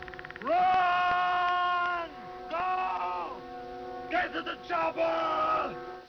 I just happened to stumble across this classic from The Governator, Arnie, in his flick Predator:
chopper.wav